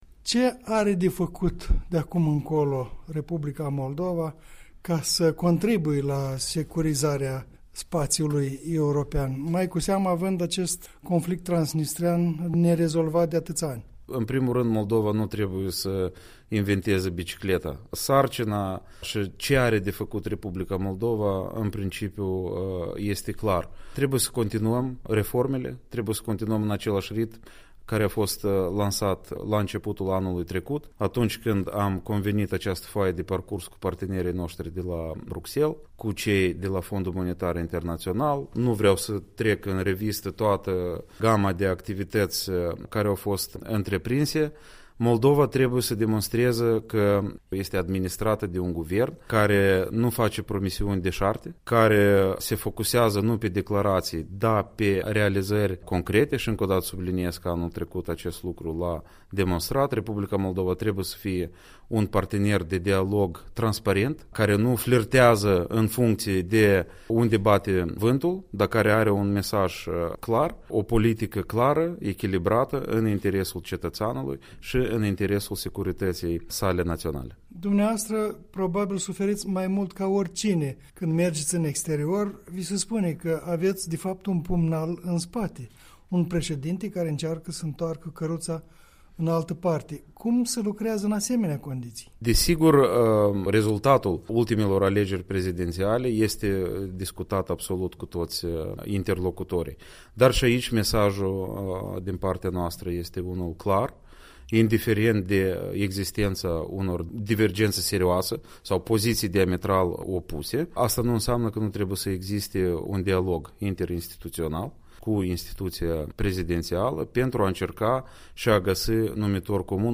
Interviu cu Andrei Galbur